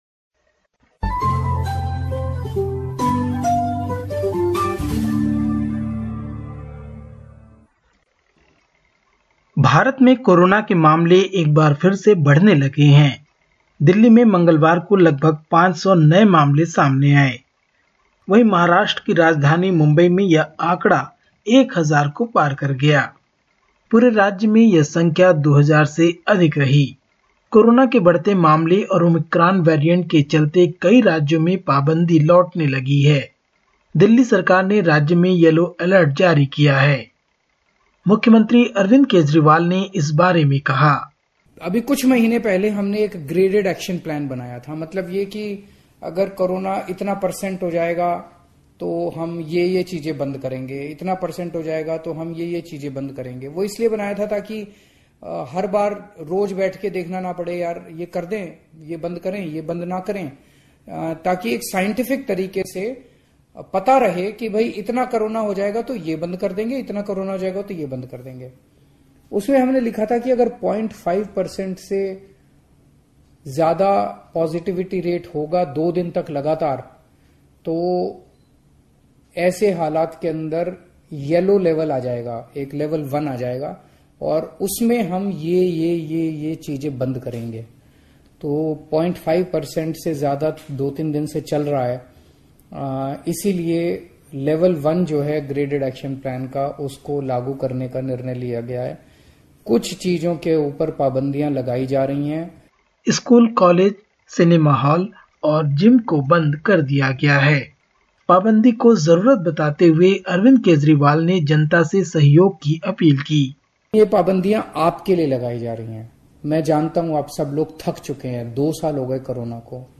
India report: Indian government extends Covid restrictions